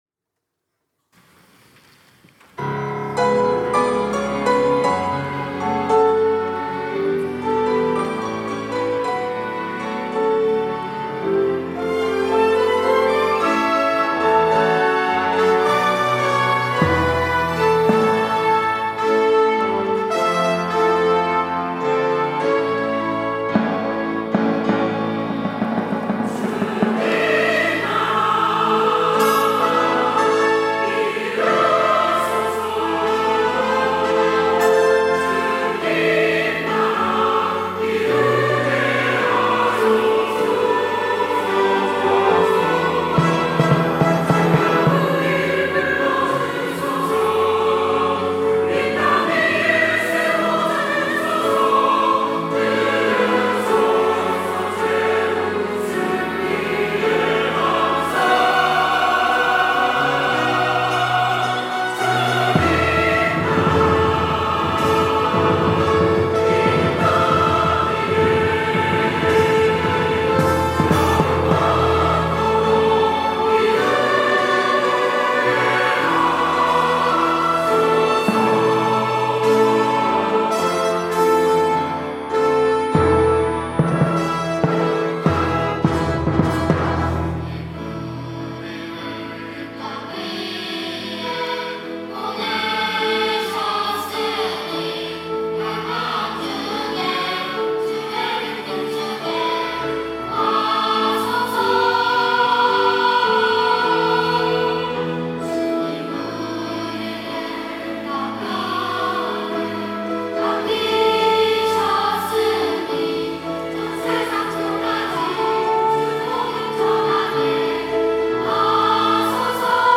특송과 특주 - 주님 나라 이루게 하소서
시니어, 가브리엘, 호산나 찬양대